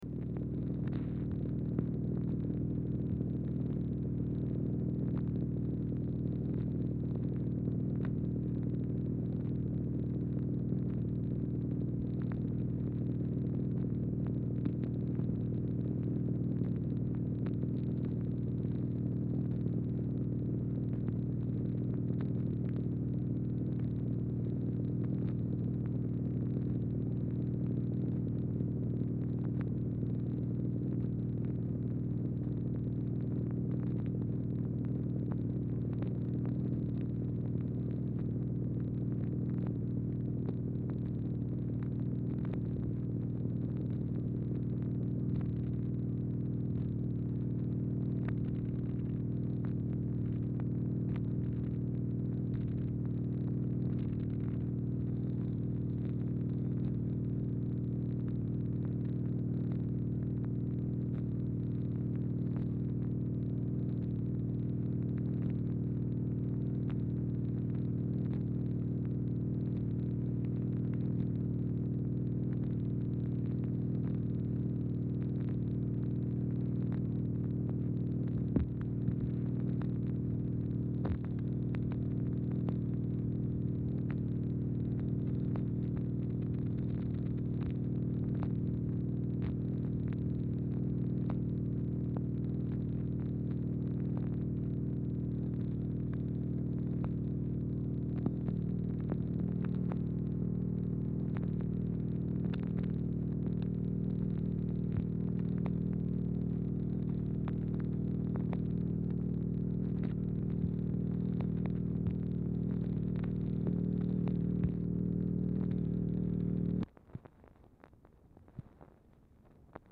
Format Dictation belt
Speaker 2 MACHINE NOISE Specific Item Type Telephone conversation